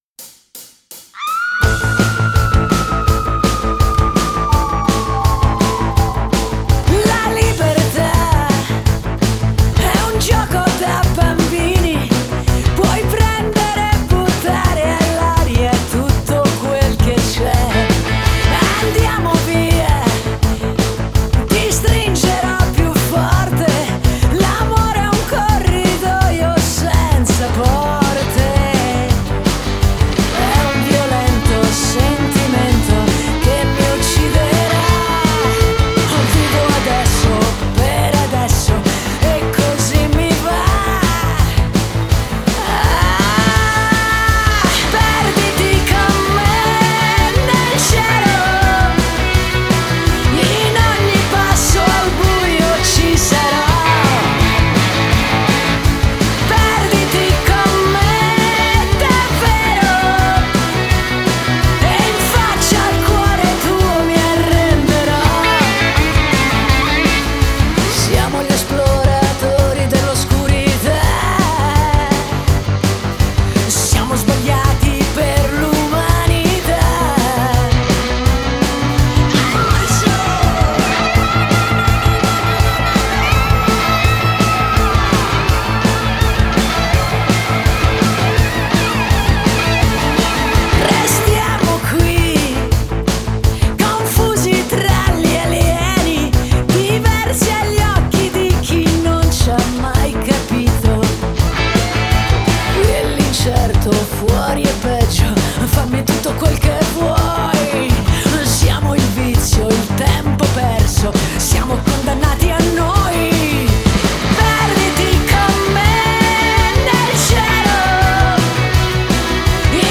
Итальянская эстрада